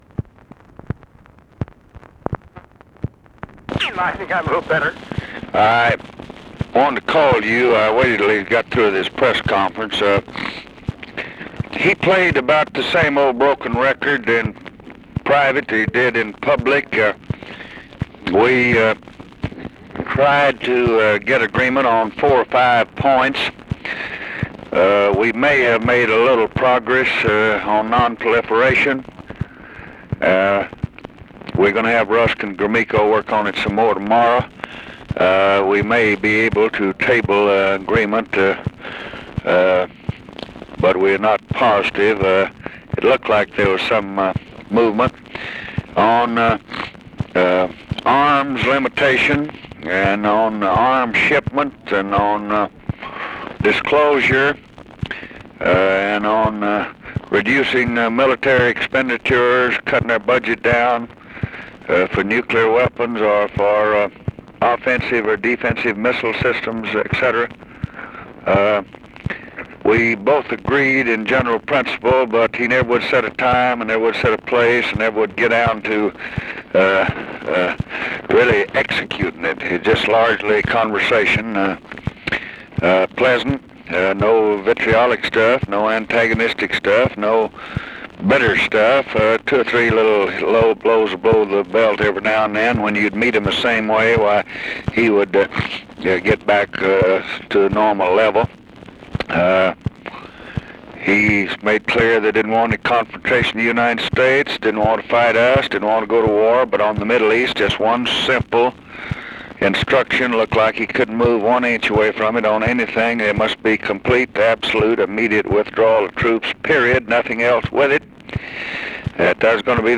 Conversation with DWIGHT EISENHOWER, June 26, 1967
Secret White House Tapes